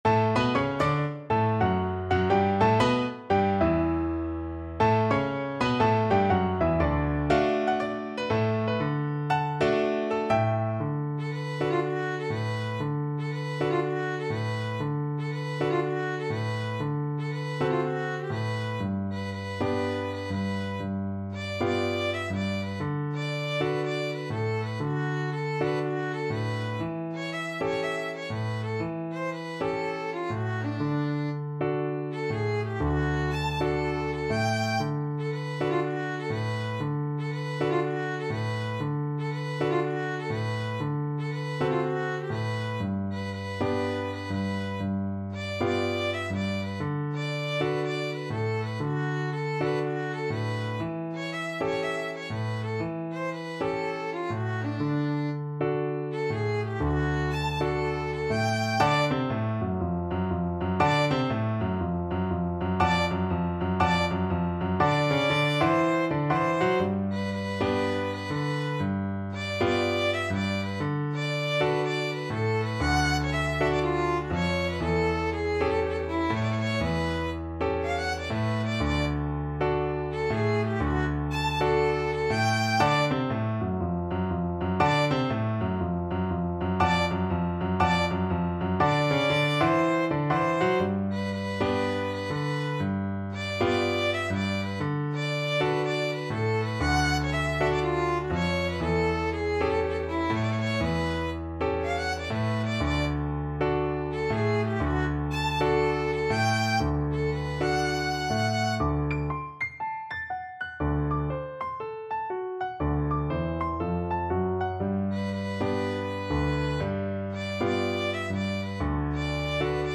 4/4 (View more 4/4 Music)
Moderato = 120
Jazz (View more Jazz Violin Music)